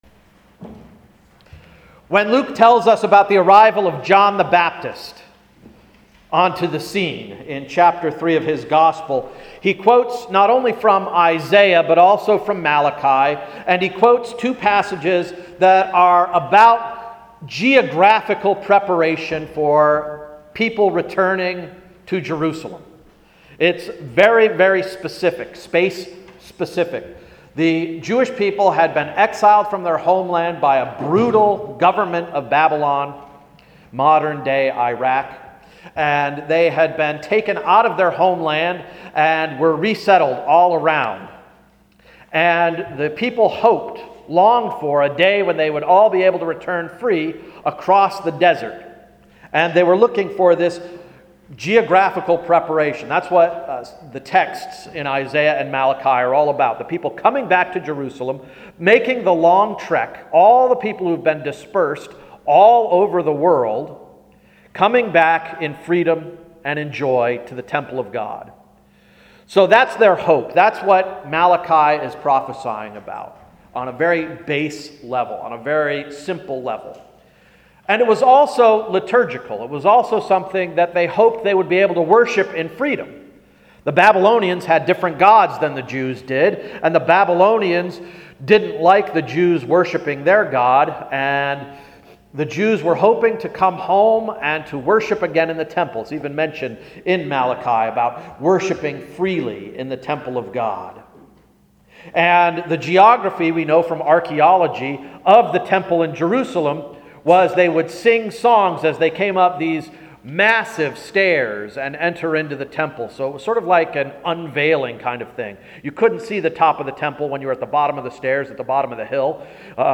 Sermon of December 6th–“Flash Crash”